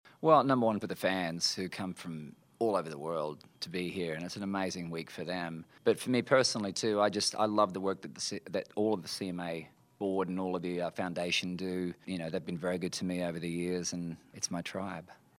Audio / Keith Urban talks about CMA Music Fest.